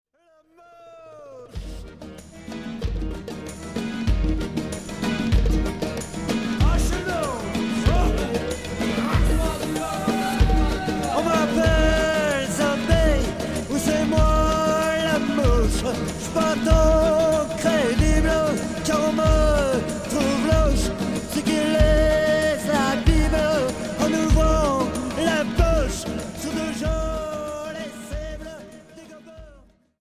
Folk Rock chanson